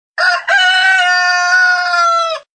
Suoneria Gallo
Categoria Animali